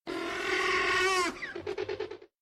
Raptor regular call
raptor-regular-call.mp3